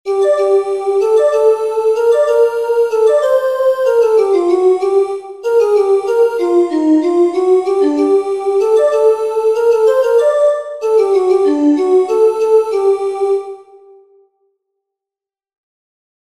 Entoación a capella
Imos practicar a entoación a capella coas seguintes melodías.
entonacion7.3capela.mp3